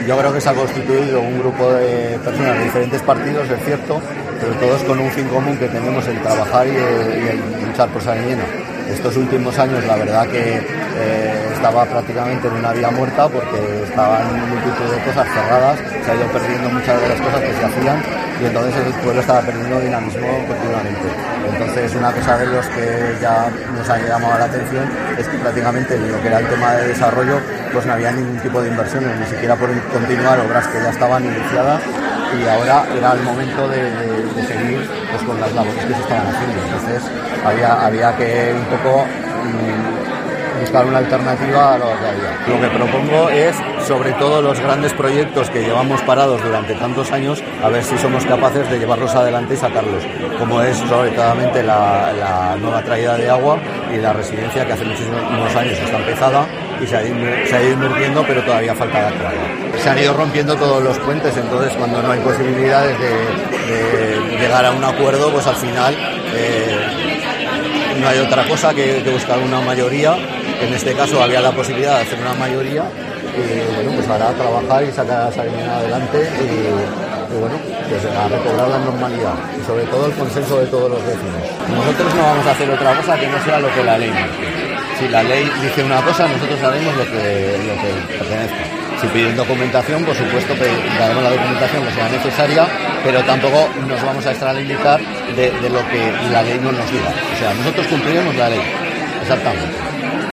El alcalde de Sariñena, Francisco Villellas tras el pleno de investidura